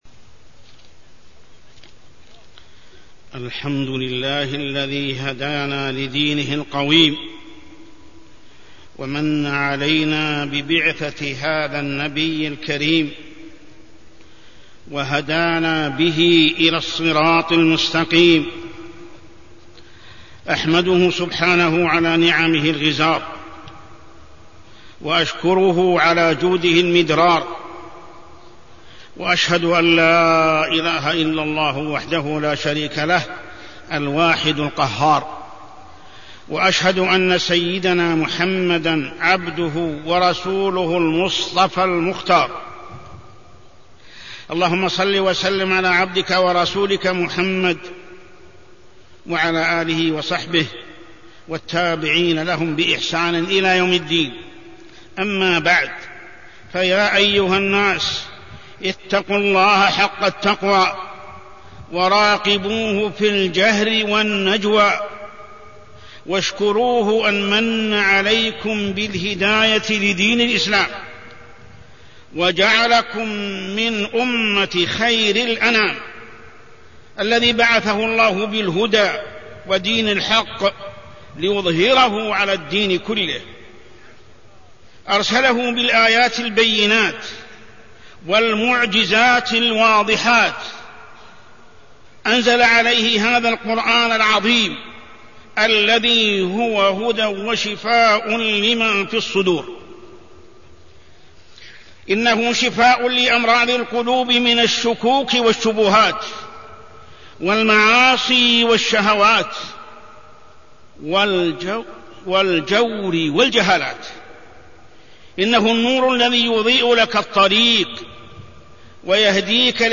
تاريخ النشر ٩ ربيع الأول ١٤٢٢ هـ المكان: المسجد الحرام الشيخ: محمد بن عبد الله السبيل محمد بن عبد الله السبيل بدعة الإحتفال بالمولد